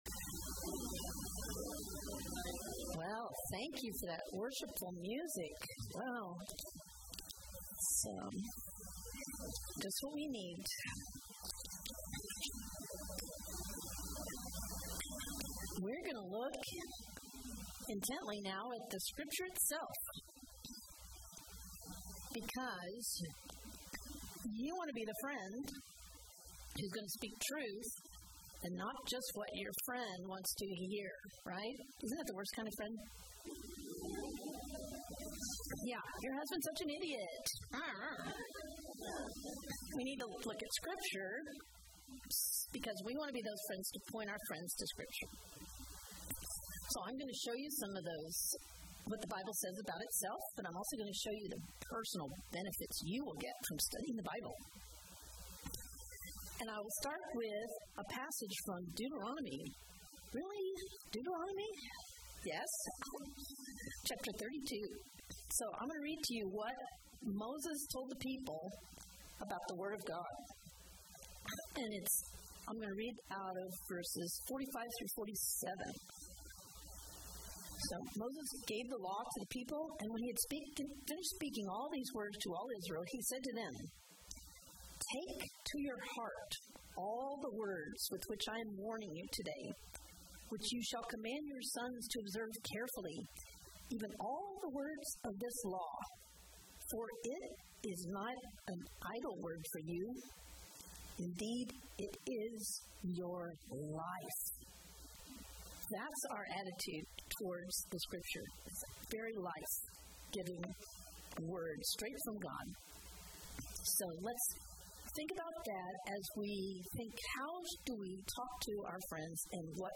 Sermons Podcast